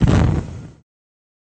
Шум розжига горелки